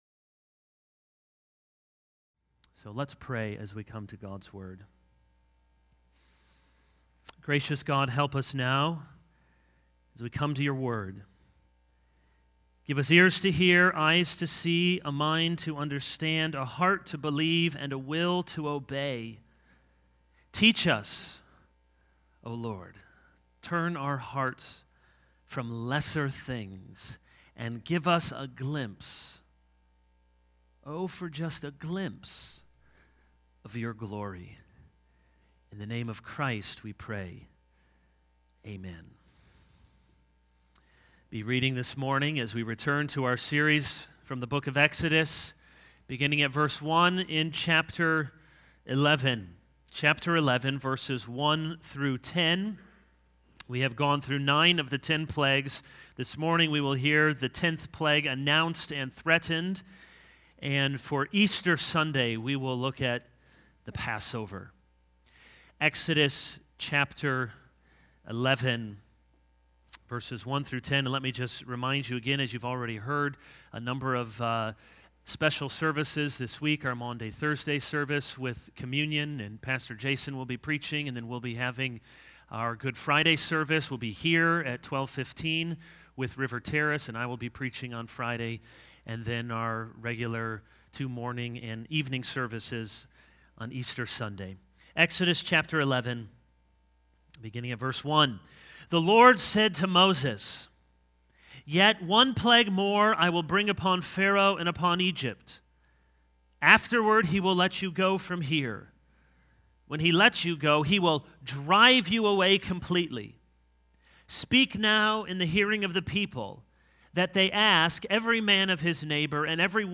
This is a sermon on Exodus 11:1-10.